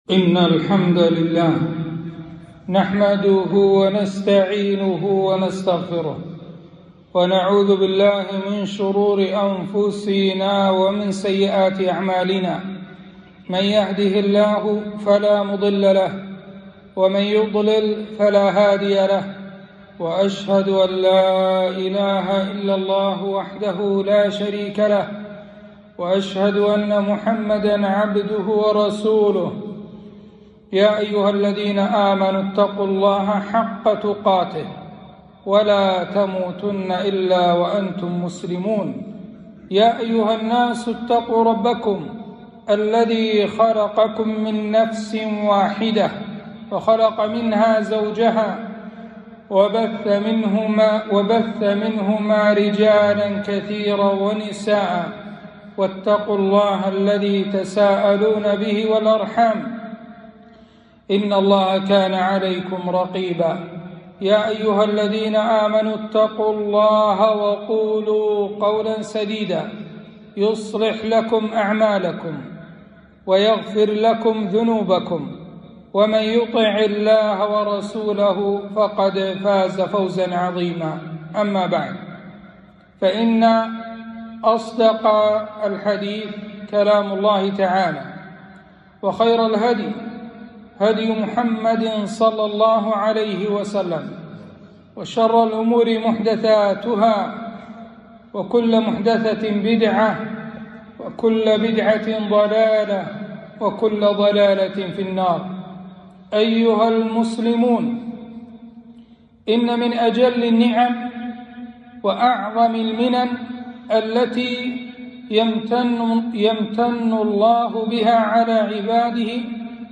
خطبة - (والله جعل لكم من بيوتكم سكنا)